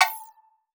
Game Reminder Notification or Alert.wav